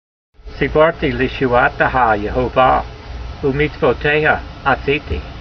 Sound (Psalm 119:166) Transliteration: see bar t ee lee shoo'ah' te' ha yeho vah , oo ' meetsvo tey ha a see tee Vocabulary Guide: I have waited (in hope) for your salvation Jehovah, and (I) have done your commandment s . Translation: I have waited (in hope) for your salvation Jehovah, and have done your commandments.
v166_voice.mp3